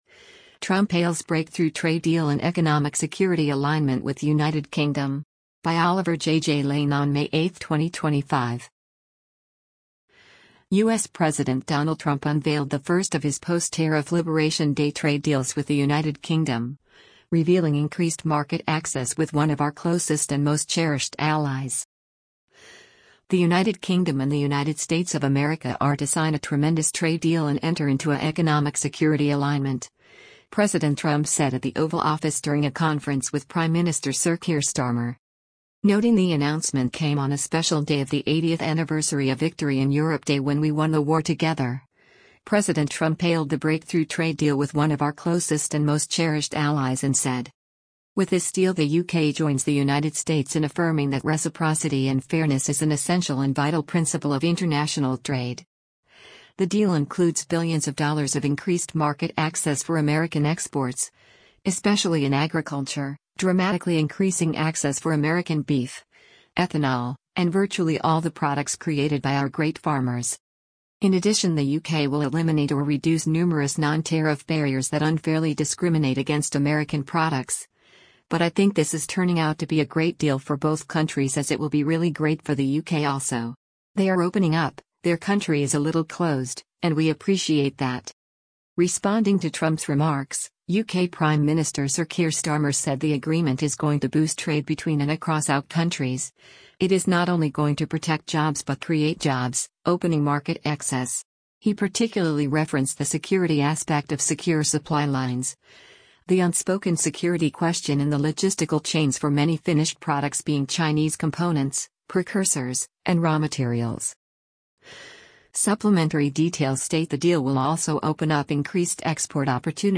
US President Donald Trump makes a trade announcement as US Secretary of Commerce Howard Lu
The United Kingdom and the United States of America are to sign a “tremendous trade deal” and enter into a “economic security alignment”, President Trump said at the Oval Office during a conference with Prime Minister Sir Keir Starmer.